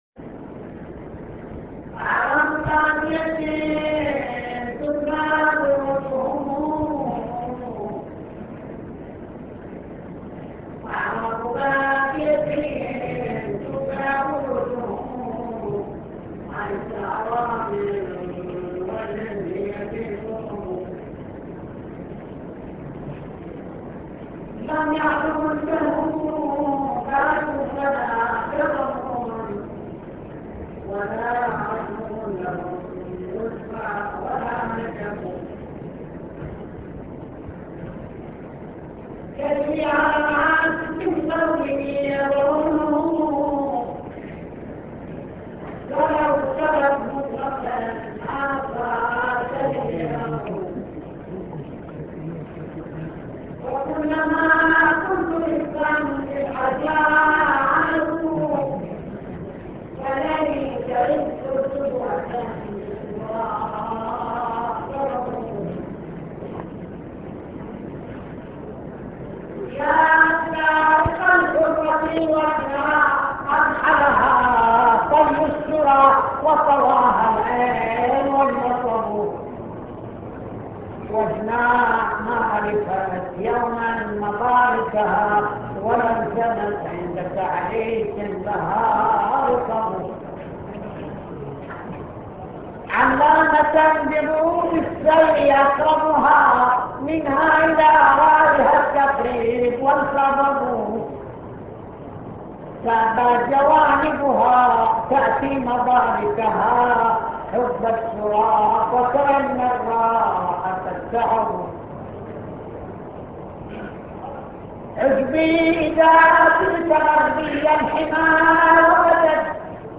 نعي حسيني